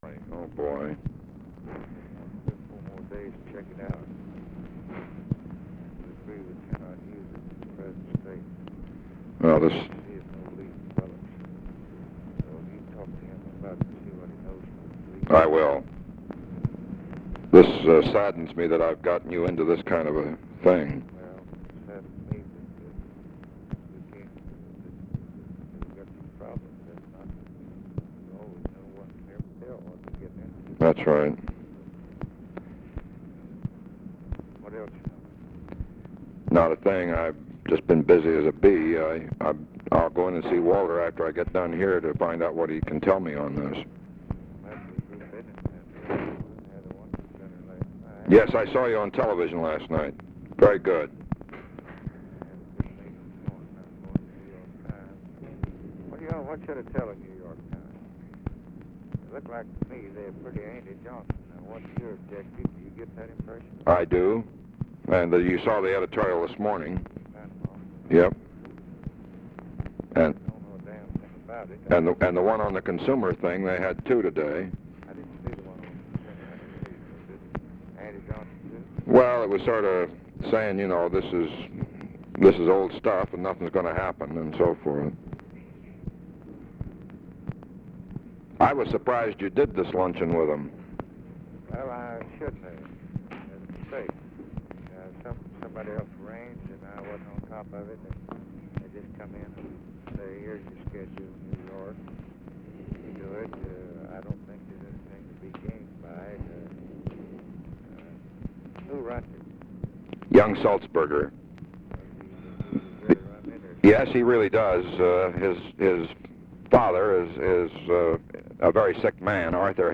Conversation with FRANK STANTON, February 6, 1964
Secret White House Tapes